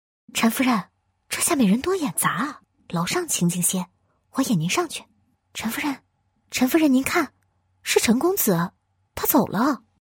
女19-动画角色《小丫鬟 心机少女》
女19-青春甜美（中英） 素人自然
女19-动画角色《小丫鬟 心机少女》.mp3